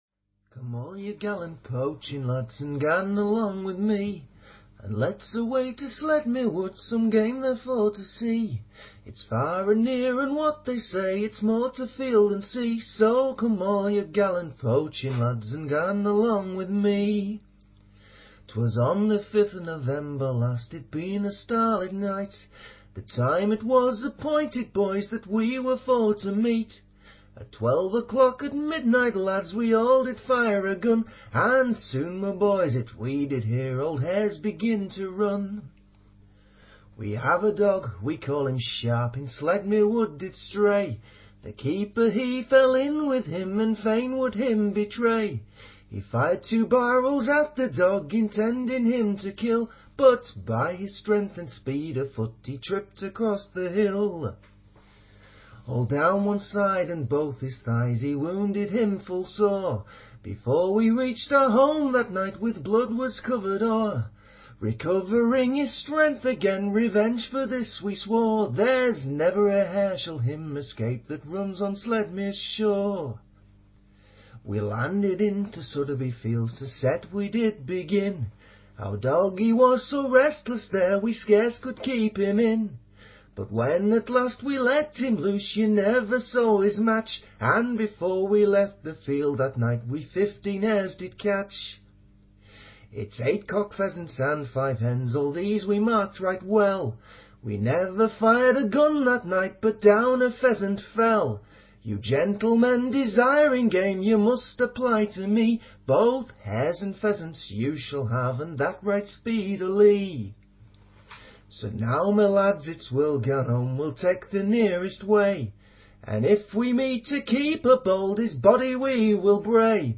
Harrogate
Ab